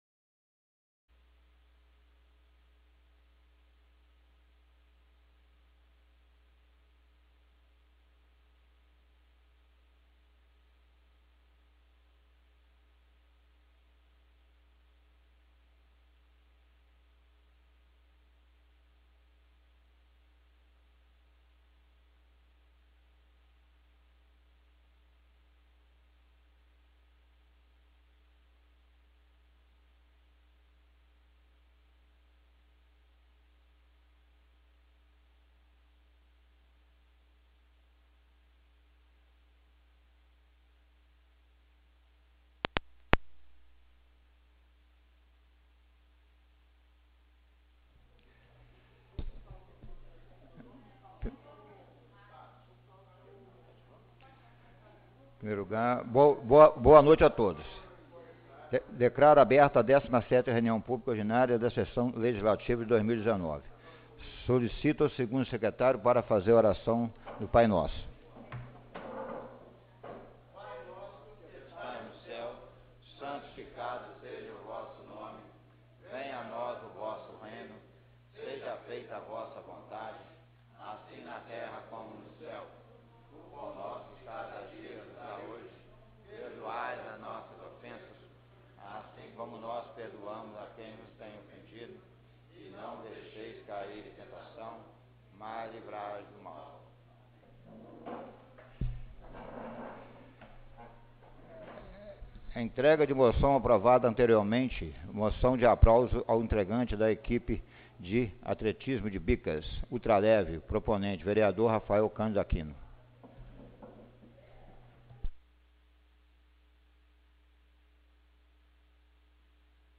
17ª Reunião Publica Ordinária